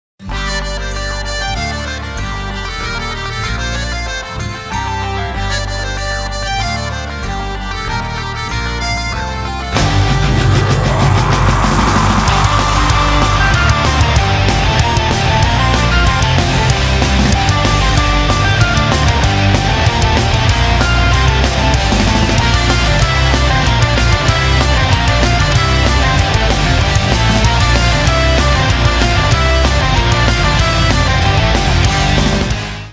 • Качество: 320, Stereo
инструментальные
Folk Rock
волынка
эпичные
folk metal
black metal